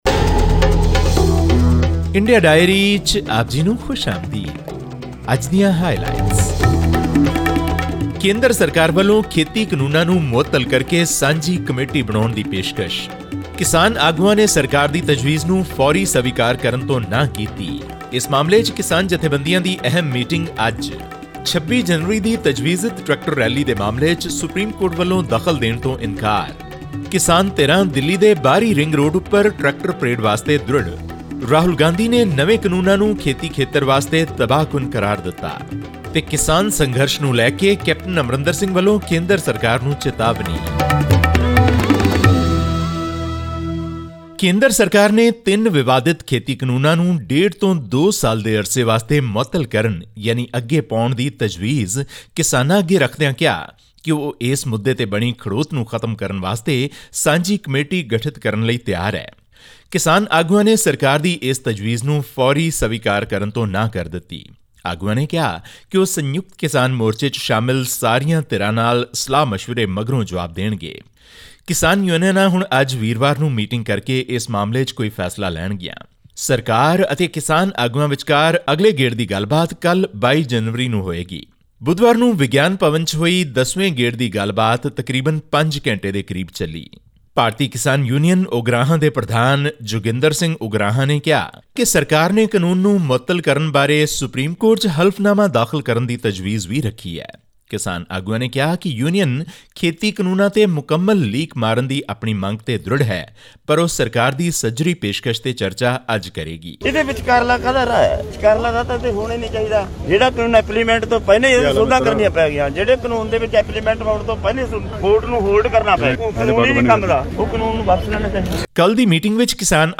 The Indian government has proposed to suspend the controversial agriculture laws for a year-and-a-half, provided the farmers call off their protest. Tune into this week's news wrap from India for this story and more.